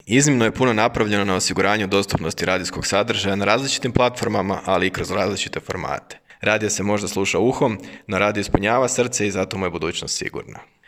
Tim povodom u petak je tradicionalno održan 5. Hrvatski radijski forum, na kojem su vodeći ljudi iz kulturne i kreativne industrije govorili upravo o Radiju i kreativnoj ekonomiji.